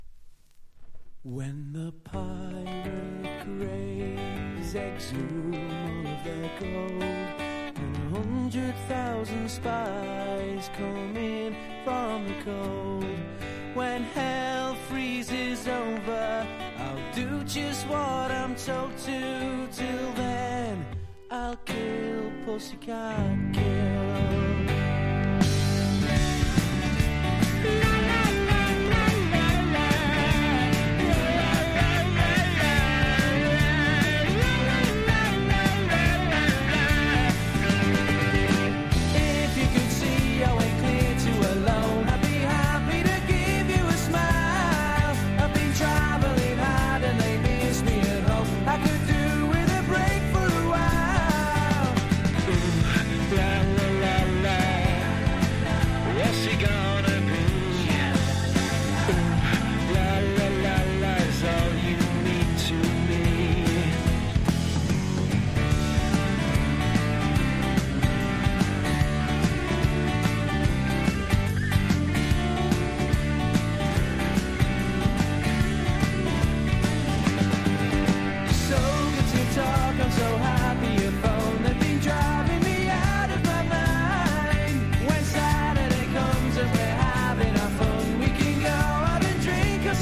1. 90'S ROCK >
NEO ACOUSTIC / GUITAR POP